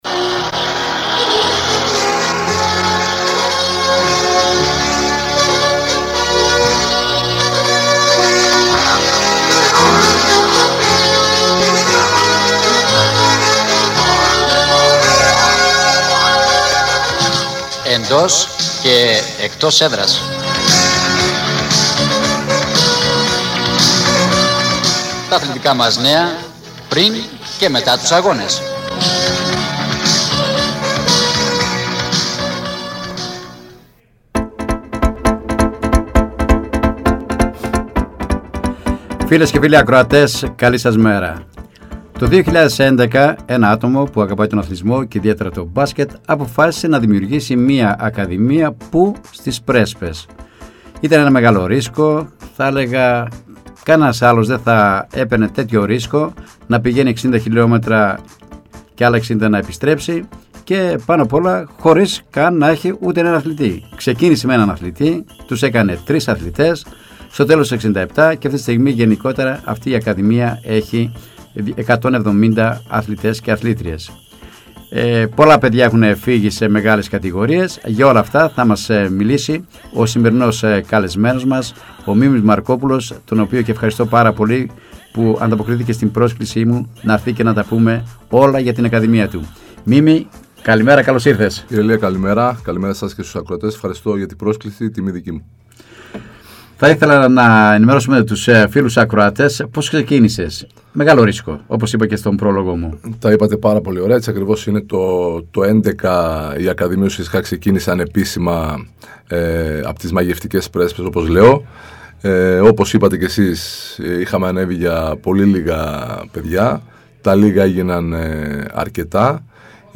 “Εντός και Εκτός Έδρας” Εβδομαδιαία αθλητική εκπομπή με συνεντεύξεις και ρεπορτάζ της επικαιρότητας, στην περιφερειακή Ενότητα Φλώρινας.